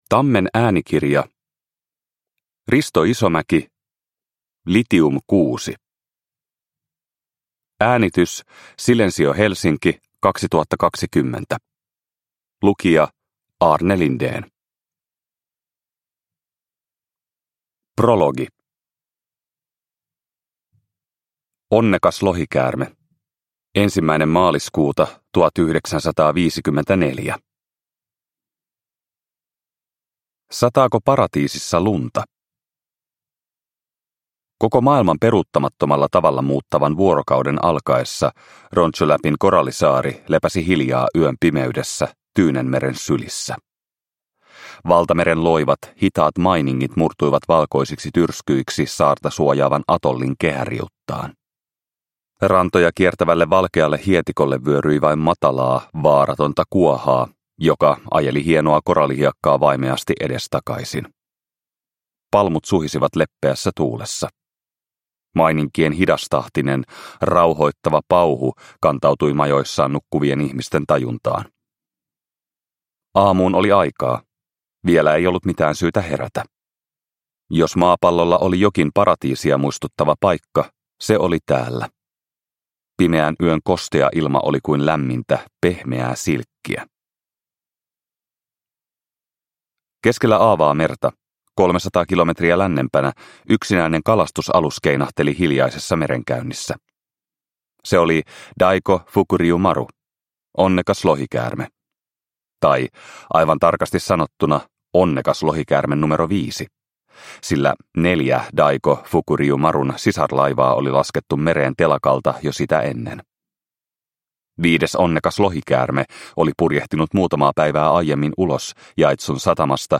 Litium 6 – Ljudbok – Laddas ner